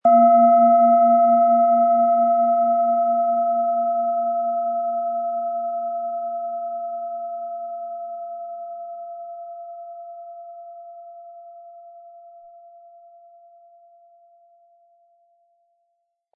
Planetenschale® Sensibel und Einfühlend sein & Geborgen fühlen mit Mond, Ø 10,6 cm, 100-180 Gramm inkl. Klöppel
Planetenton 1
Sie möchten den schönen Klang dieser Schale hören? Spielen Sie bitte den Originalklang im Sound-Player - Jetzt reinhören ab.
Sanftes Anspielen mit dem gratis Klöppel zaubert aus Ihrer Schale berührende Klänge.
SchalenformBihar
MaterialBronze